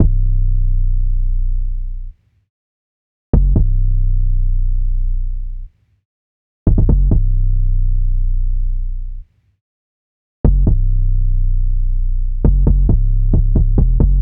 SOUTHSIDE_beat_loop_cash_808_135_B.wav